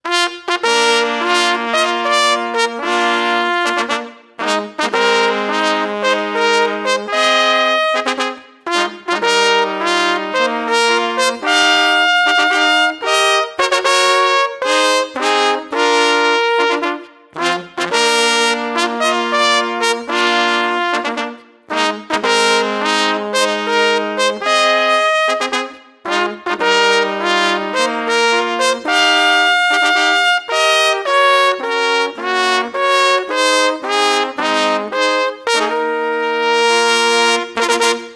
Jednocześnie harmonia i rytmika są zdecydowanie nowoczesne.
na trzy trąbki